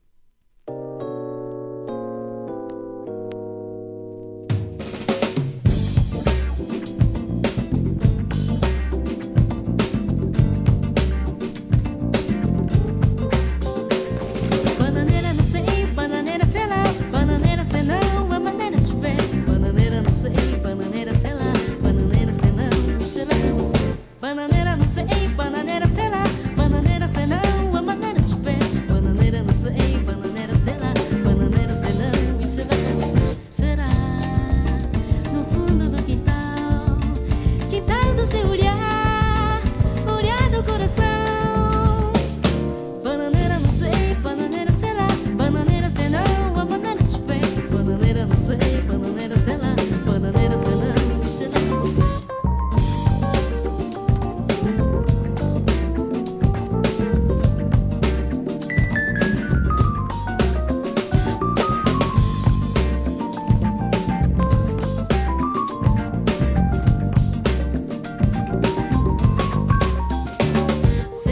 12inch
1. HIP HOP/R&B